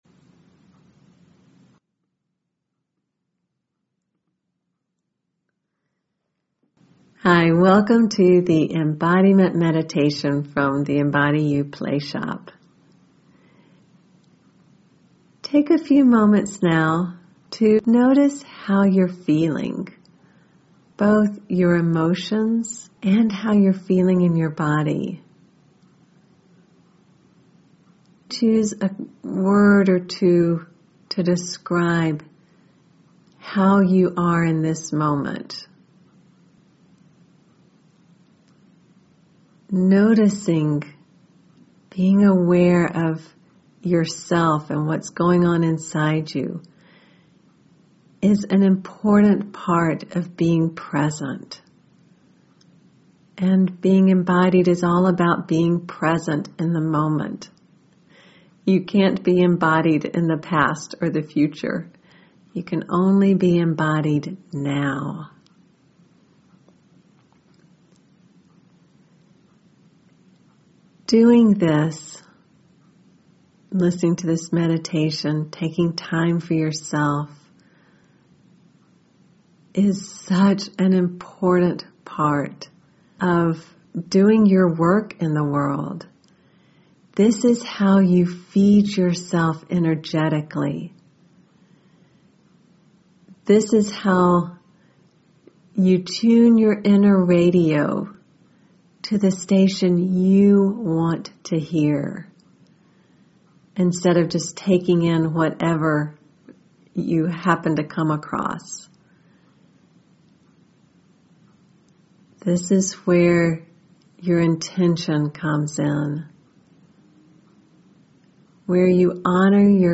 Embody You Meditation Click here to download the 16-minute Embody You meditation.